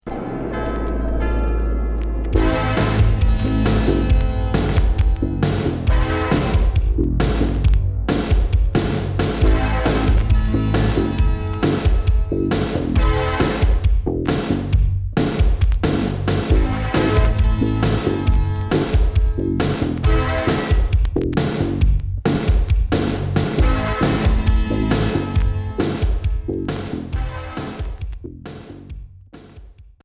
Rock new wave Unique 45t retour à l'accueil